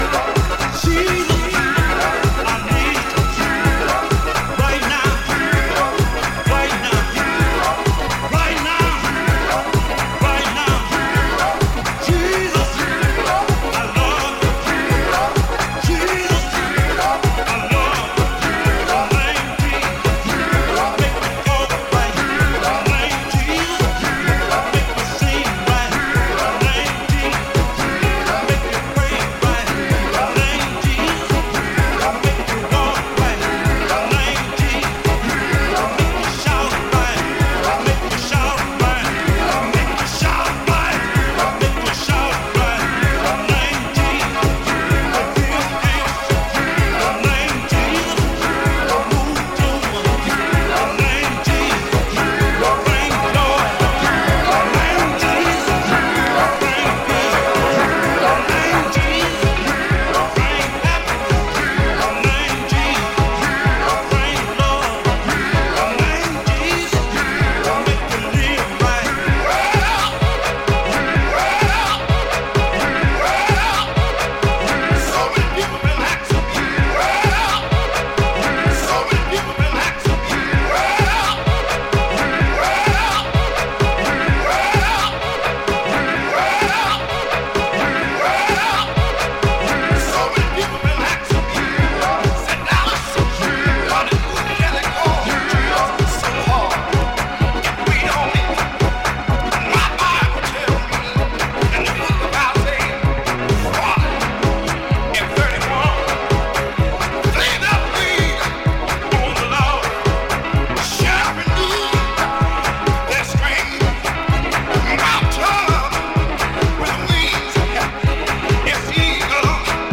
ゴスペリッシュでソウルフルな歌唱と共にグイグイ突き進む